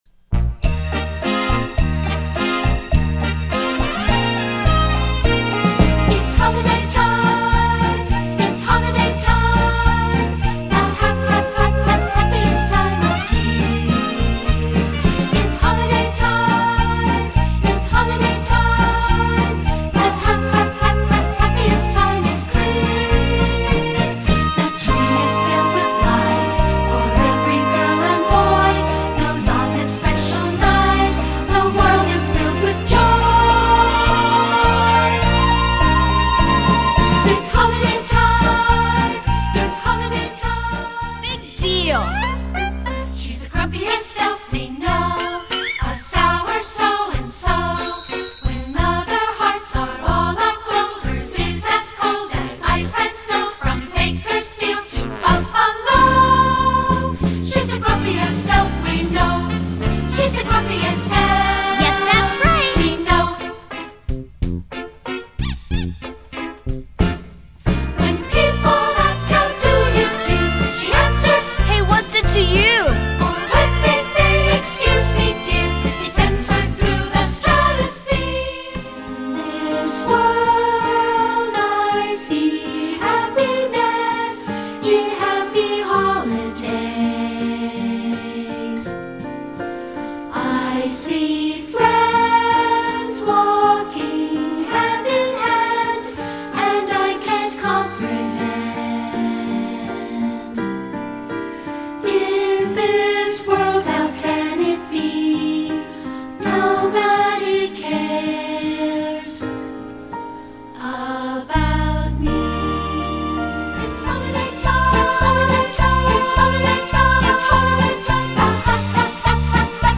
General Music Musical Plays Holiday